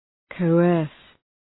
Προφορά
{kəʋ’ɜ:rs}